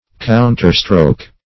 Counterstroke \Coun"ter*stroke`\ (-str?k`), n.